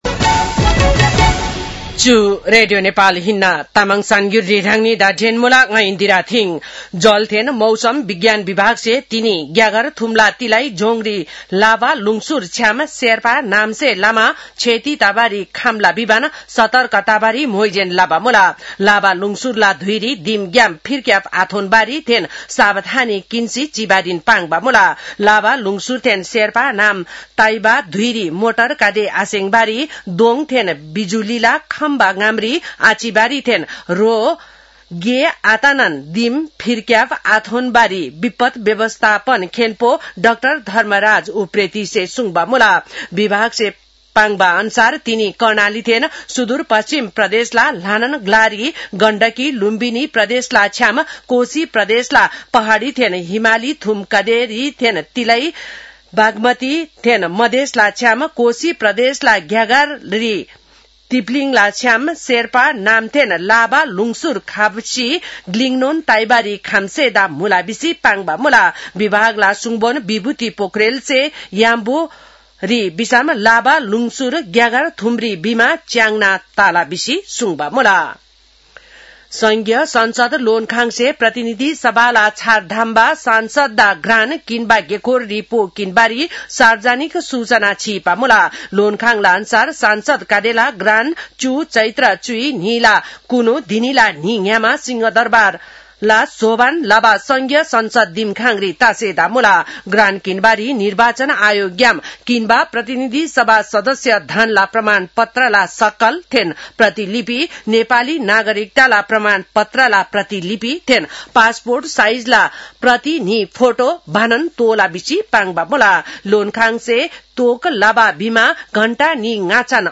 तामाङ भाषाको समाचार : ६ चैत , २०८२
Tamang-news-12-06.mp3